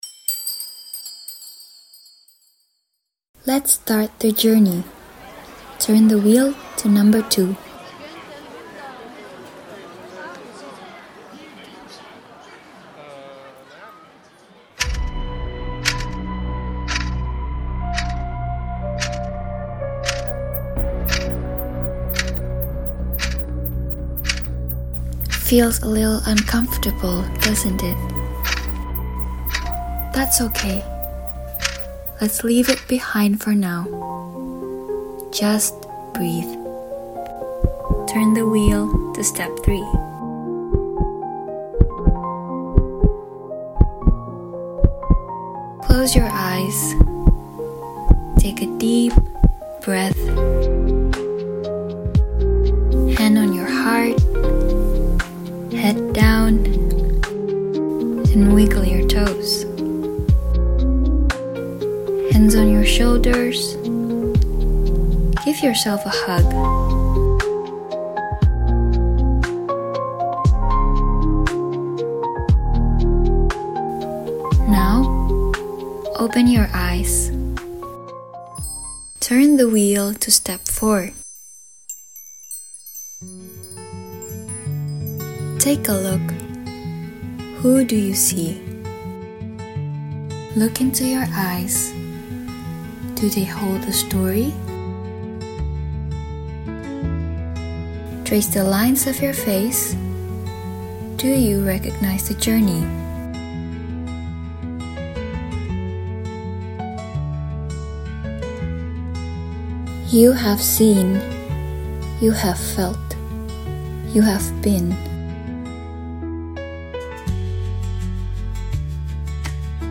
选择一个安静的角落，戴上耳机，扫描二维码，开始音频引导旅程。
专注于音乐与声音的引导。跟随语言的节奏，将你的情绪带入身体。在镜子前观察自己，让当下的你慢慢沉静下来，引导思绪轻柔地回望内在。